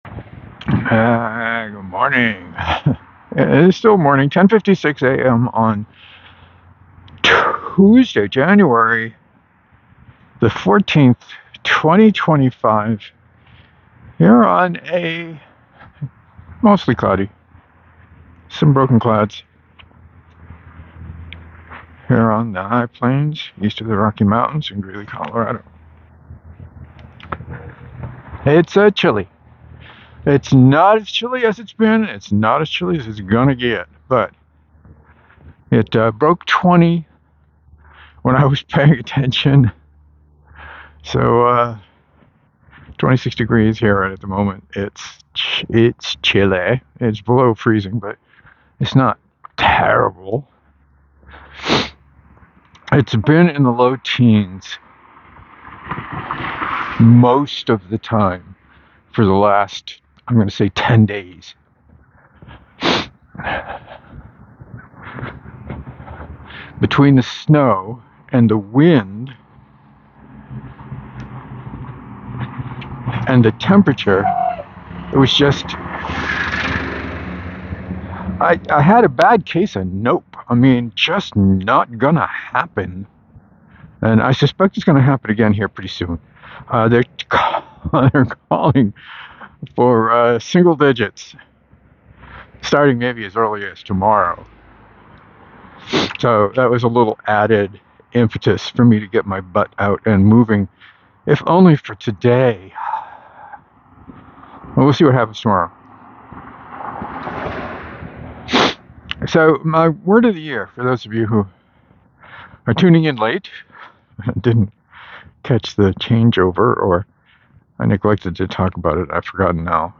The temps and conditions made the walk possible so I took advantage. Talked about my morning routine, probably too much, and what I’m trying to do with my Practice this year.